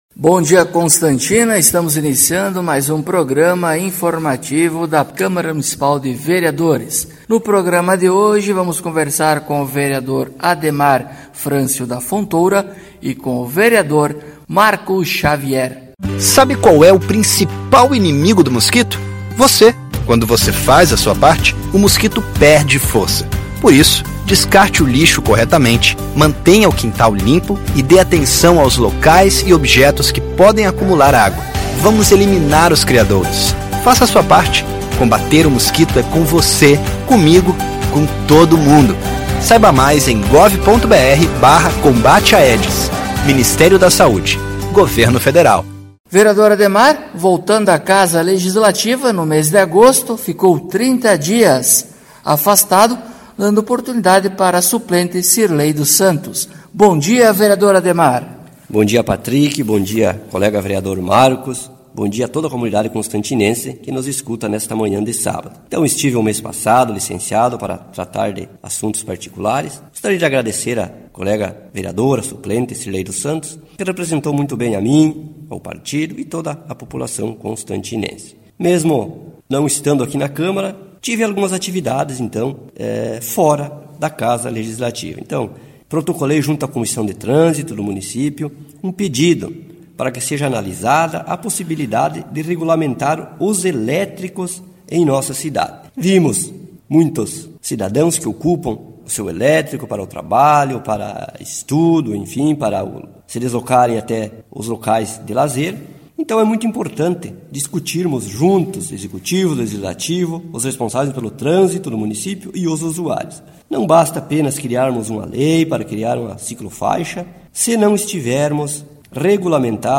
Acompanhe o programa informativo da câmara de vereadores de Constantina com o Vereador Ademar Francio da Fontoura e o Vereador Marco Xavier.